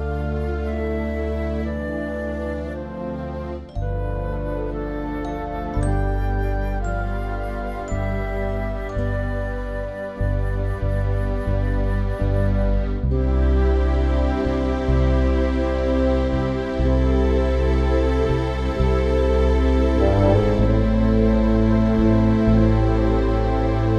no Backing Vocals Musicals 3:39 Buy £1.50